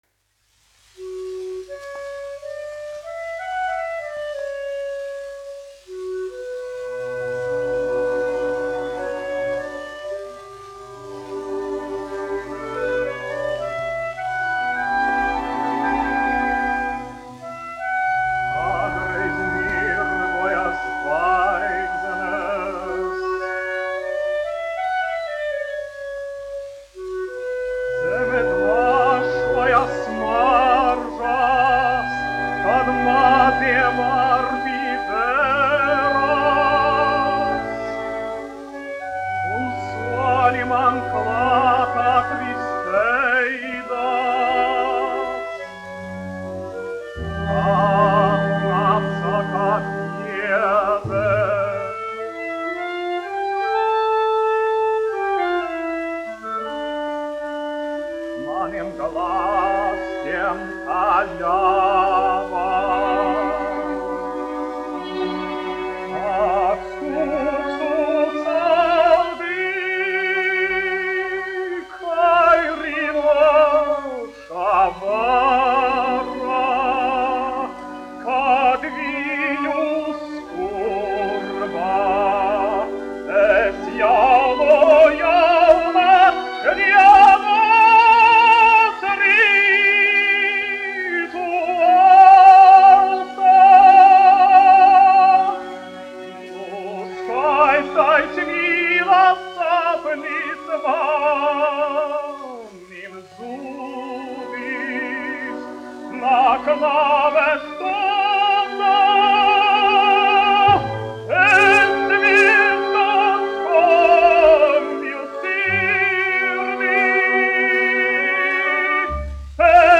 Latvijas Nacionālās operas orķestris, izpildītājs
1 skpl. : analogs, 78 apgr/min, mono ; 25 cm
Operas--Fragmenti
Latvijas vēsturiskie šellaka skaņuplašu ieraksti (Kolekcija)